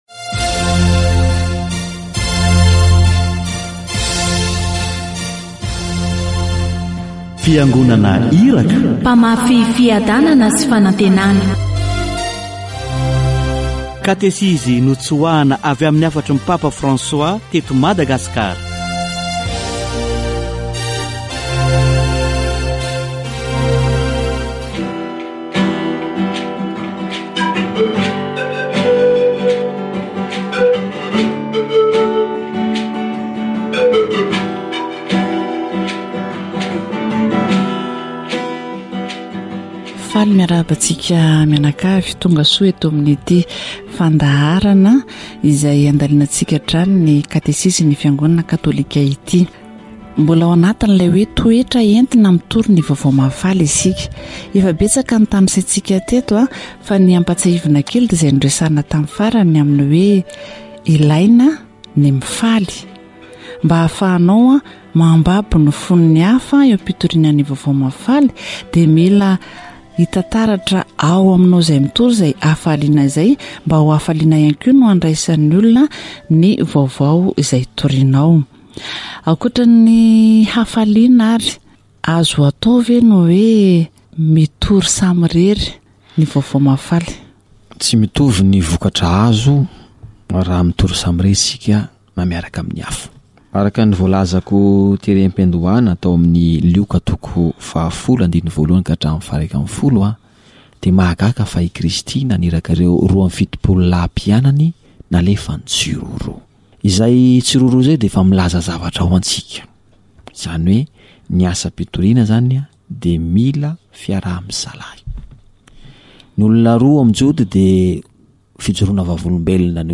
Catégorie : Approfondissement de la foi
Les laïcs ont le devoir d'être le levain au milieu du monde pour que la paix, la justice et l'amour soient vécus sur la terre. Ceux qui sont évangélistes doivent maîtriser les outils de communication et le type de langage qui existe aujourd'hui. Catéchese sur "Prêcher l'Evangile"